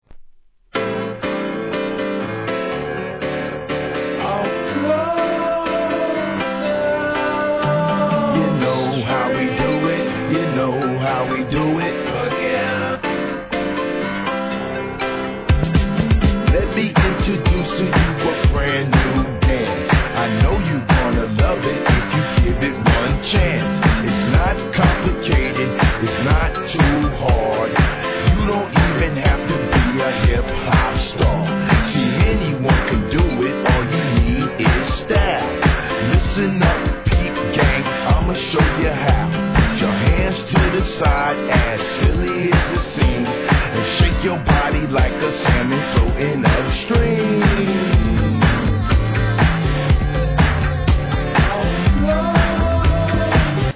12inch
1. HIP HOP/R&B